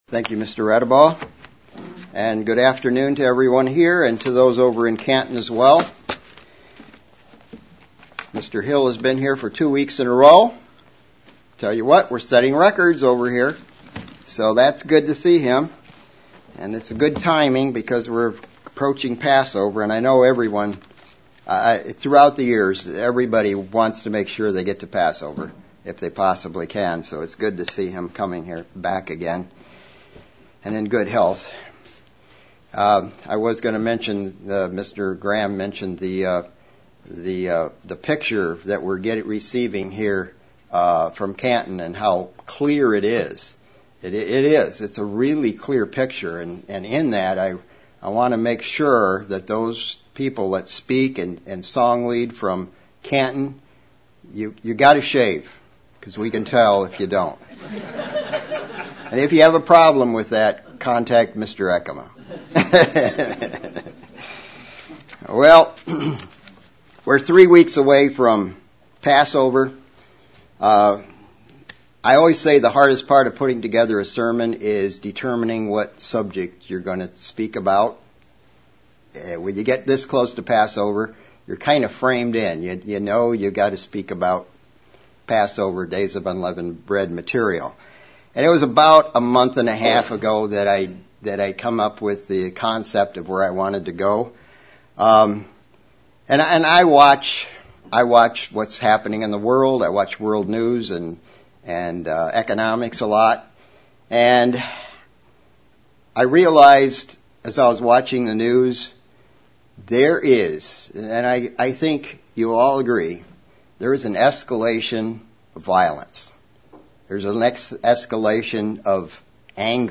Pre Passover sermon on ways we can deal with and control anger in our lives using Biblical examples and teachings from Christ